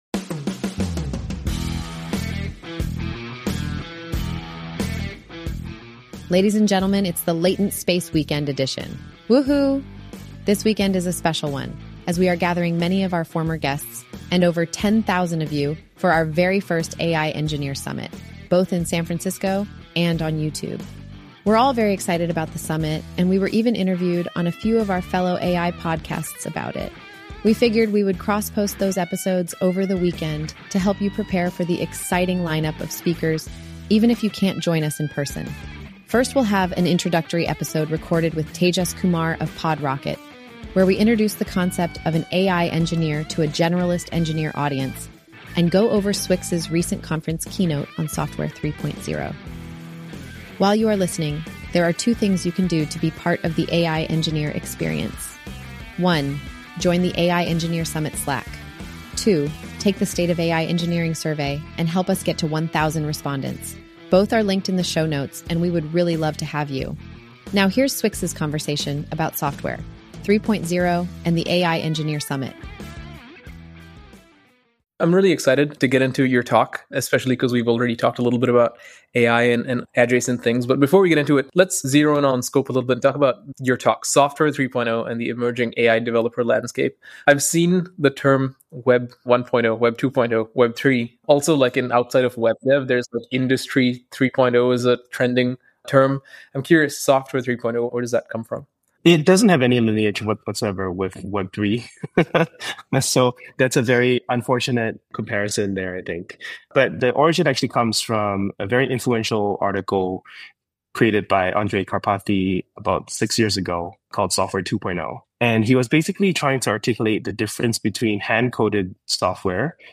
In this insightful conversation